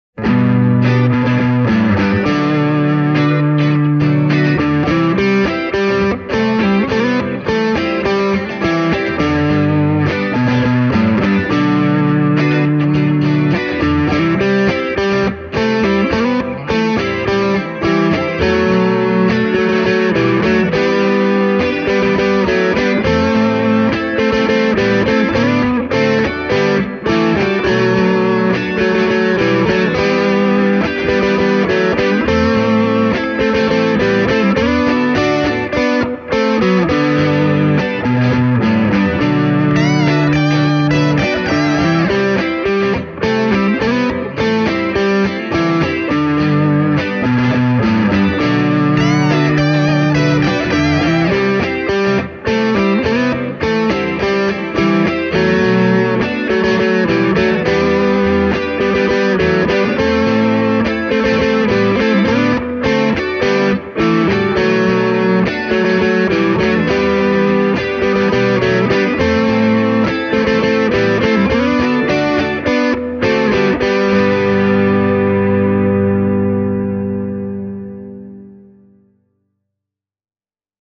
The tone of the Flaxwood MTQ doesn’t come as a surprise – this model offers an array of very tasty Tele-style sounds!